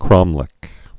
(krŏmlĕk)